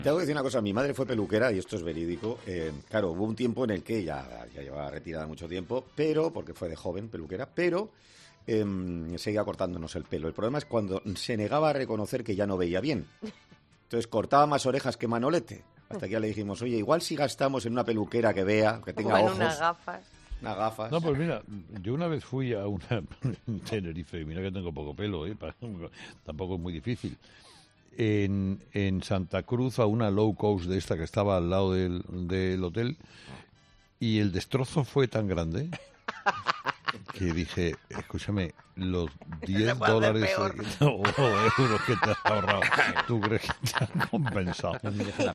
Carlos Herrera narra su experiencia en una peluquería "low cost" de Tenerife